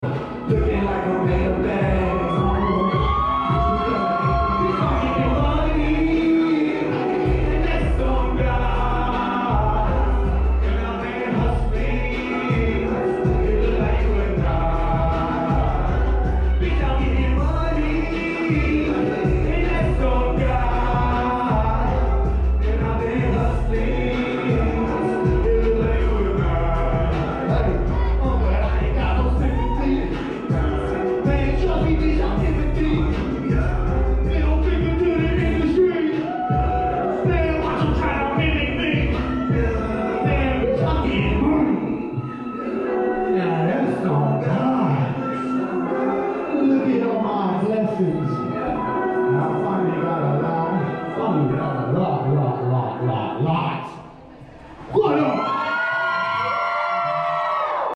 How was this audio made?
from last nights show at Montage Music Hall